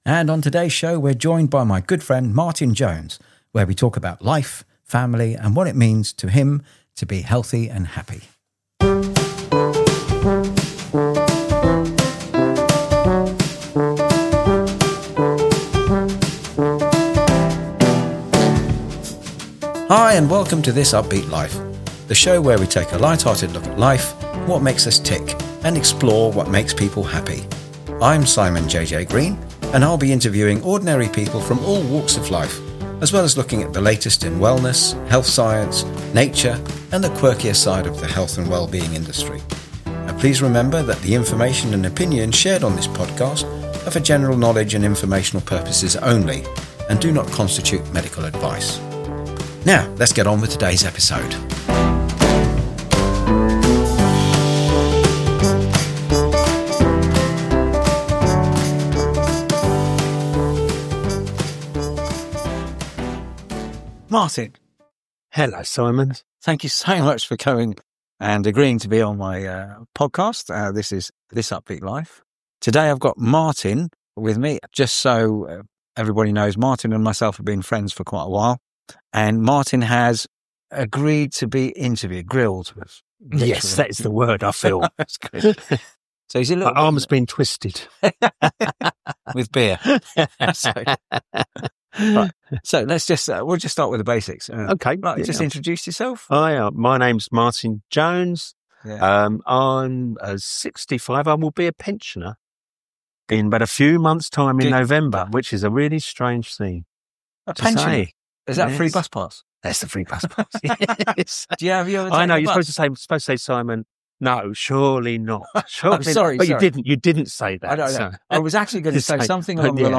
A Jolly Interview